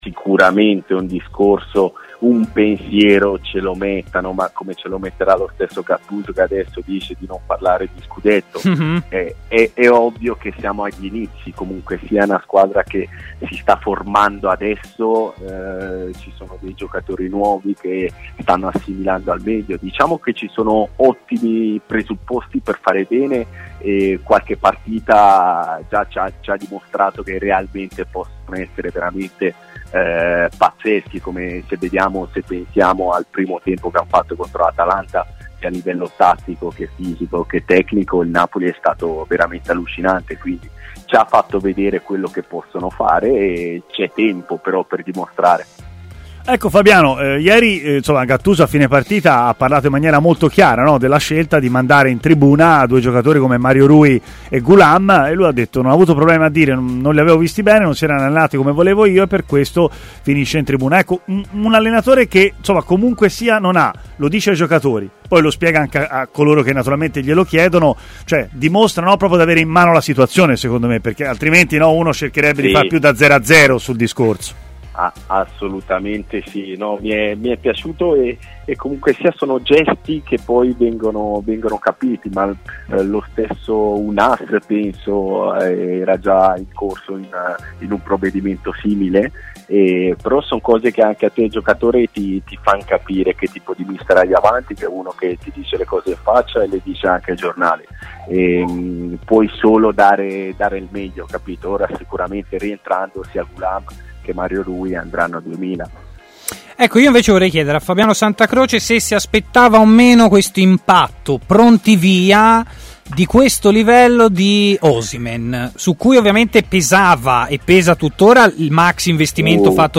Il difensore Fabiano Santacroce si è collegato in diretta con TMW Radio, intervenendo nel corso della trasmissione Stadio Aperto